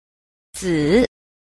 d. 子 – zi – tử